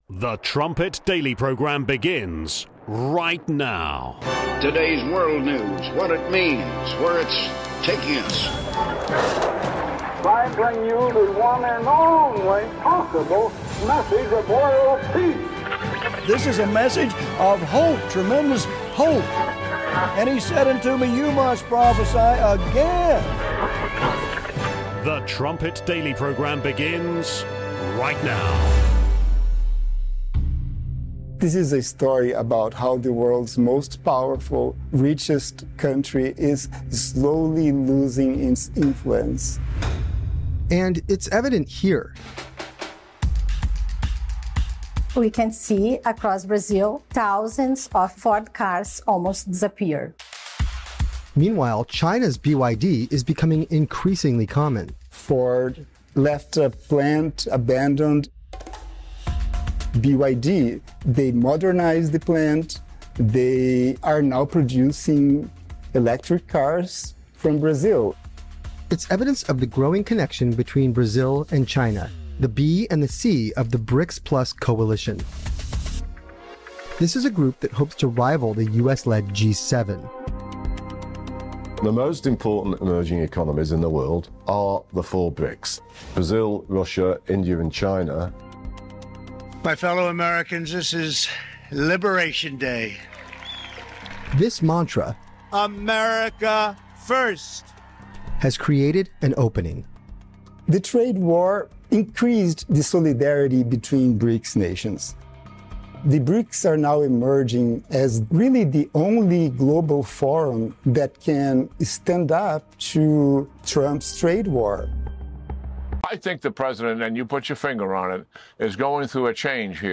26:50 Trumpet Daily Interview: Michael Oren, Part 2 (29 minutes)